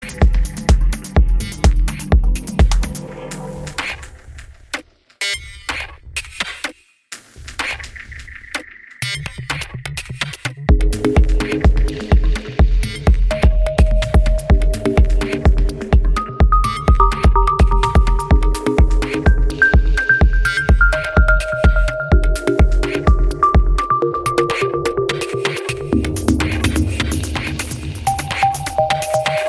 Live from Pacesetters Kitchen Radio (Malaga, Spain)
Live from Pacesetters Kitchen Radio (Malaga, Spain): Basspistol Radio (Audio) Nov 12, 2025 shows Live from Pacesetters Kitchen Radio (Malaga, Spain) Basspistol Radio Station! 777% without commercials! RobotDJ-sets and live interventions!